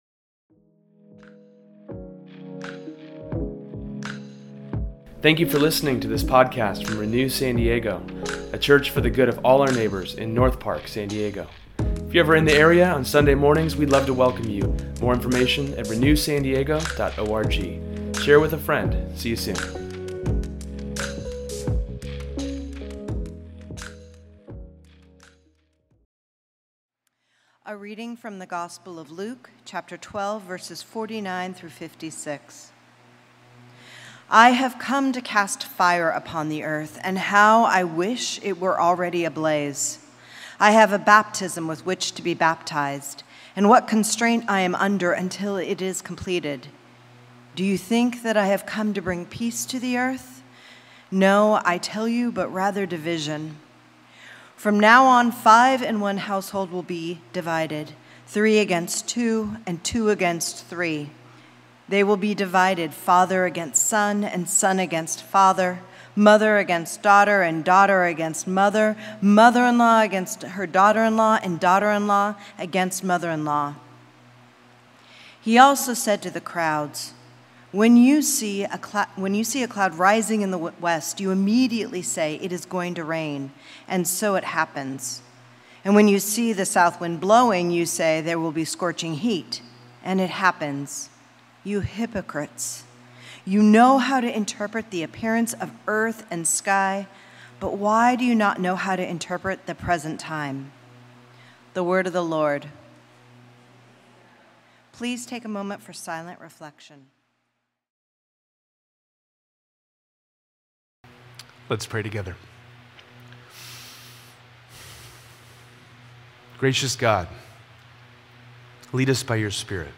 In today’s sermon we answer the questions: What do you do with the divided world we live in, and what do you do with the divisions of your heart?